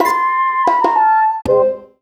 SC_Negative_Stinger_01.wav